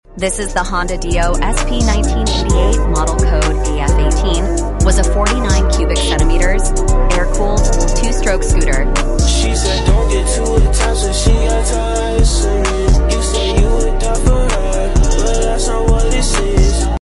Sarap Sa Ears Aht Pipe Sound Effects Free Download